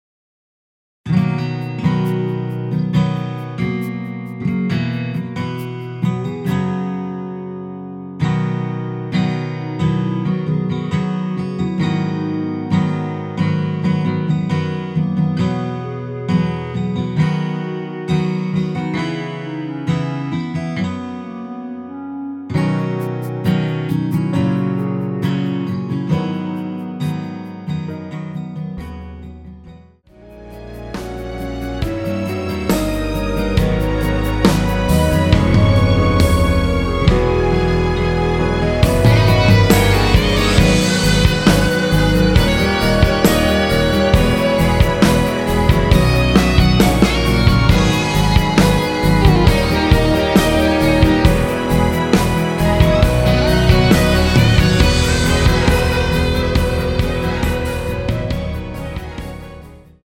원키에서(-2)내린 (1절+후렴) 멜로디 포함된 MR입니다.(미리듣기 확인)
앞부분30초, 뒷부분30초씩 편집해서 올려 드리고 있습니다.
중간에 음이 끈어지고 다시 나오는 이유는